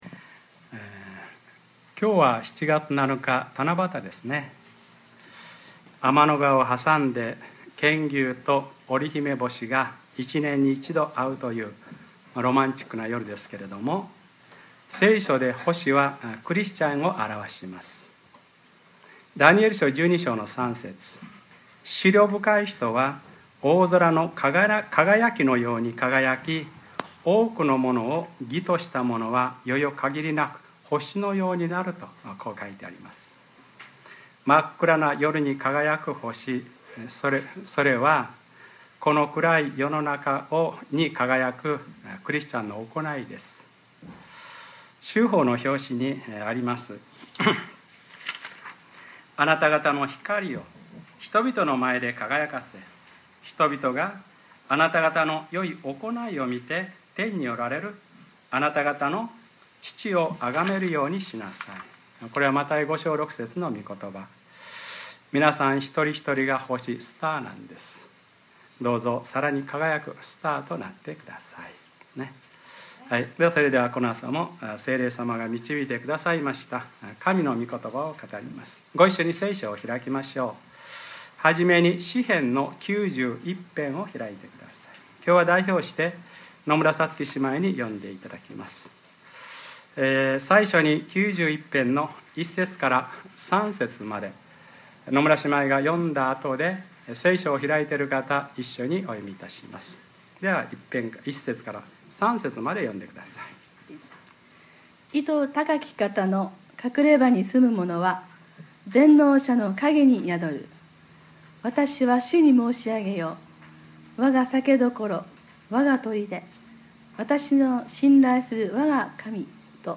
2013年7月7日(日）礼拝説教 『御使いたちに命じて、すべての道で．．．』 | クライストチャーチ久留米教会